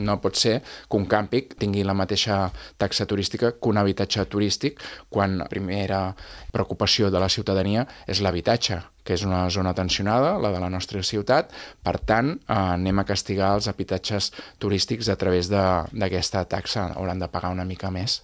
Ho ha afirmat el portaveu republicà i cap de l’oposició, Xavier Ponsdomènech, a l’Entrevista Política de Ràdio Calella TV.